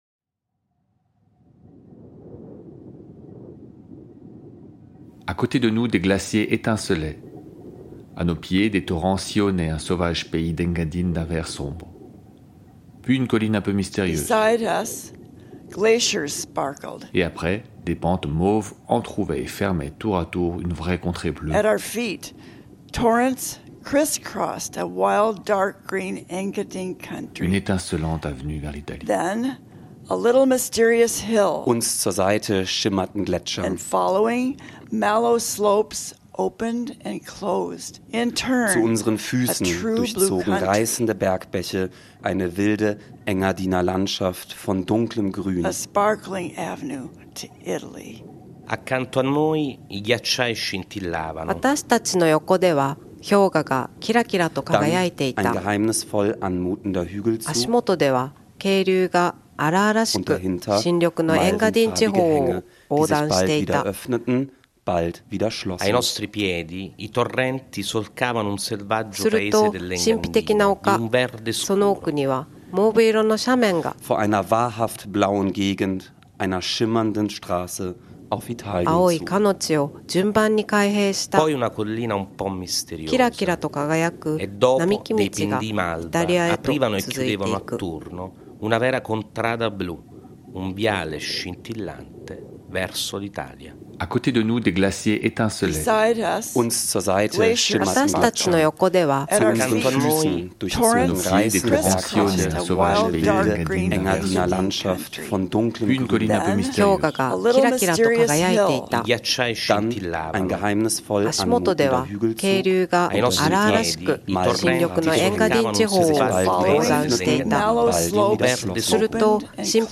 Ils conduisent le public à une installation, sculpture sonore constituée de trois tubes, comme une longue-vue orientée vers l’Italie, dont les deux tubes externes sont équipés de haut-parleurs et restituent le texte de Proust en cinq langues : allemand, italien, français, anglais et japonais.